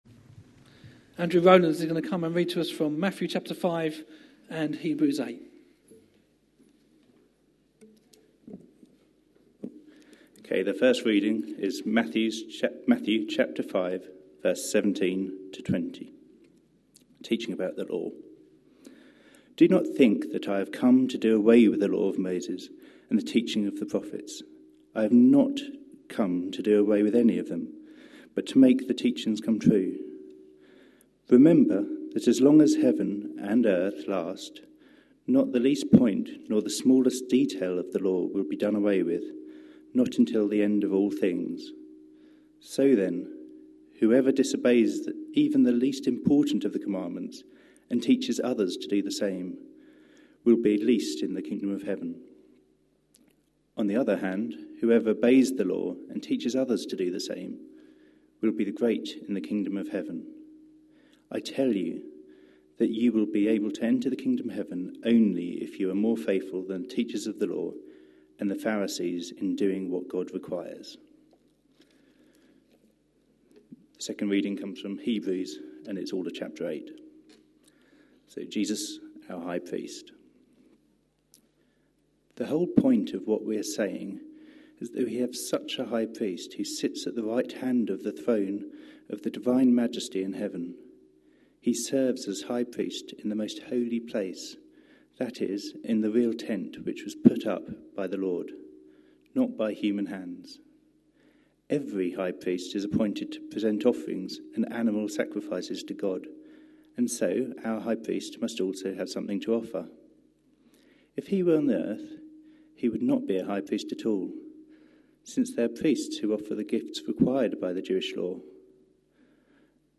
A sermon preached on 6th March, 2016, as part of our Lent 2016. series.